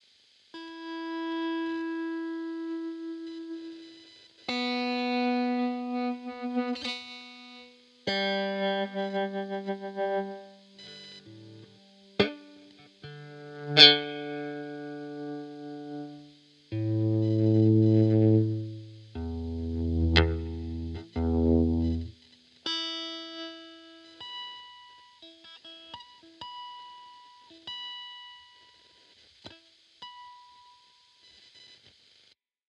そこで、出力先をオーディオインターフェースに繋ぎ、それぞれのコイルを試してみたところ、「赤コイル」だけが反応しました。
ギターとオーディオインターフェースを直接ケーブルで繋いでいないのに音が出るのは、なんだか不思議な感覚です……。